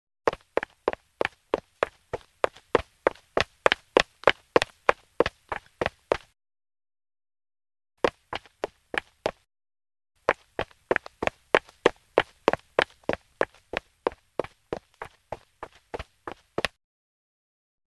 Звуки каблуков
Быстрый бег на женских каблуках